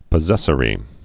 (pə-zĕsə-rē)